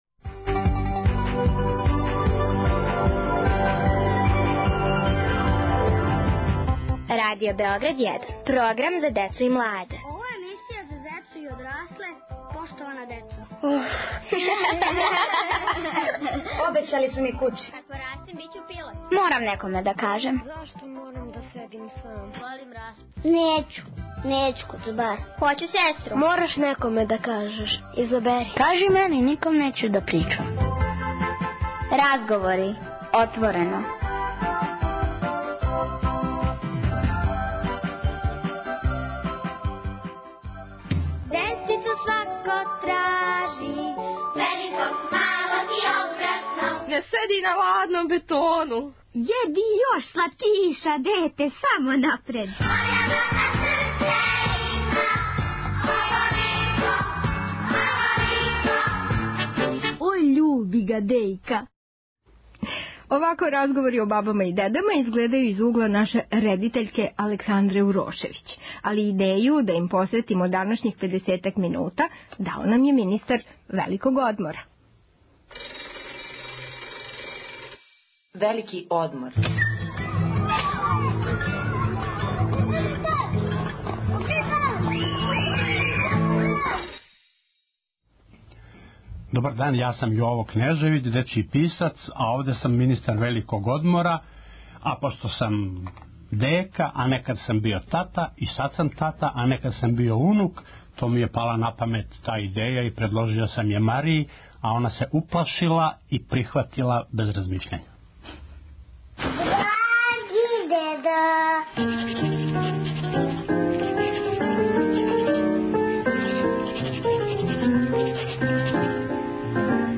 Разговори - отворено: разговарају бабе, деде, унуци и унуке...